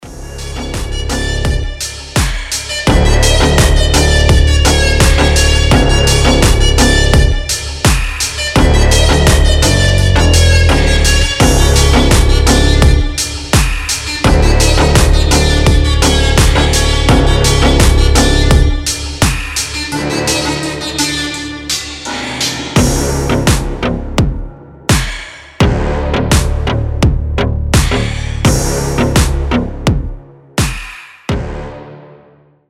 Танцевальные рингтоны
Рингтоны техно
Bass house , G-house , Мощные басы